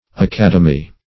Academy \A*cad"e*my\, n.; pl.